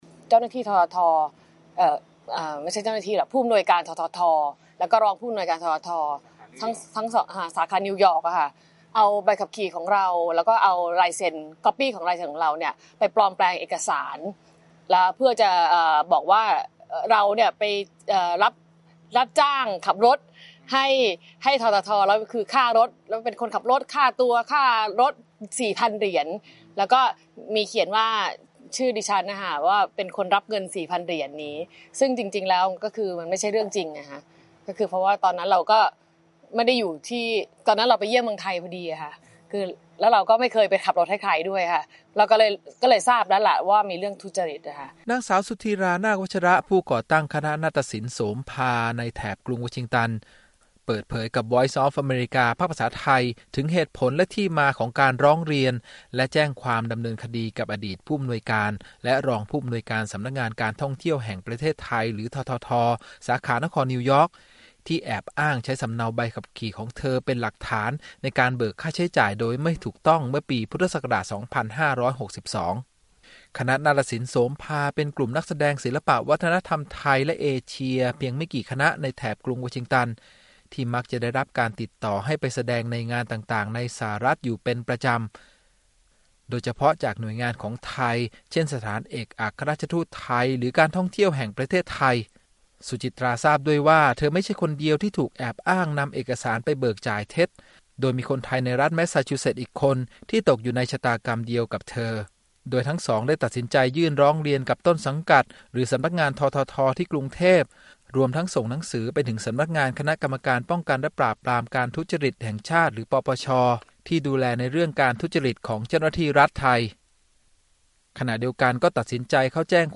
during an interview session in Silver Spring, MD.